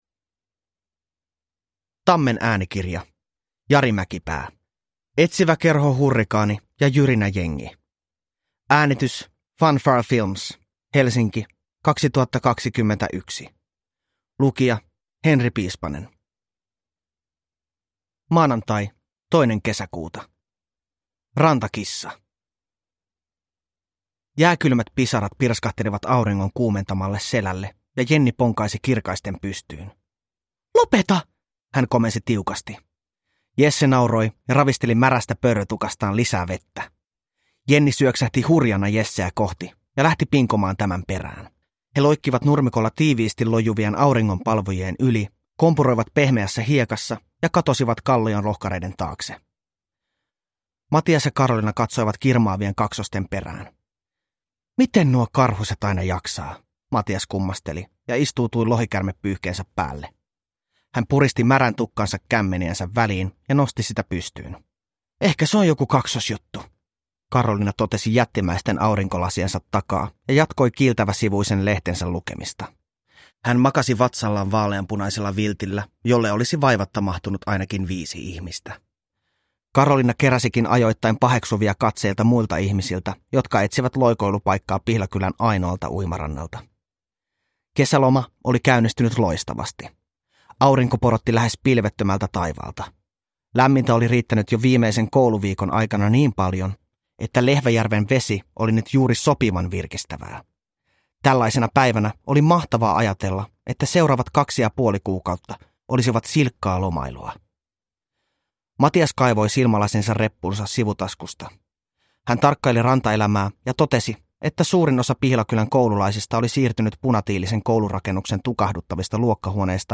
Etsiväkerho Hurrikaani ja Jyrinäjengi – Ljudbok – Laddas ner